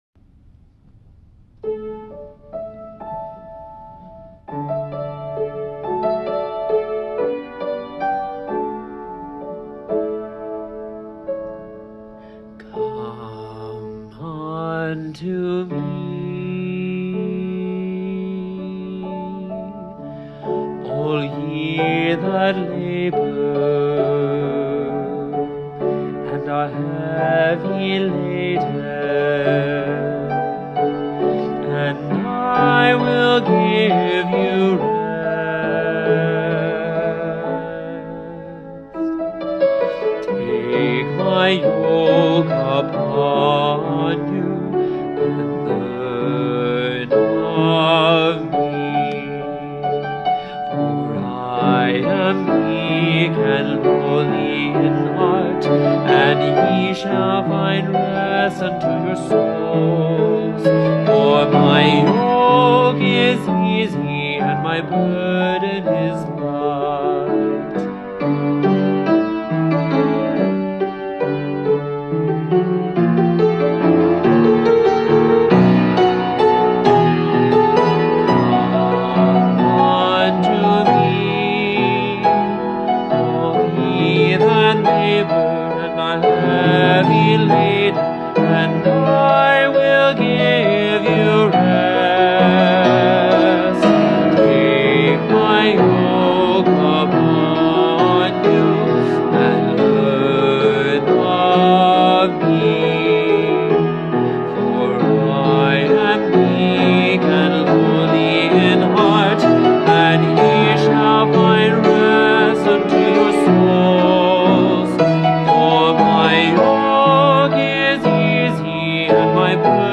SAB Choir and Piano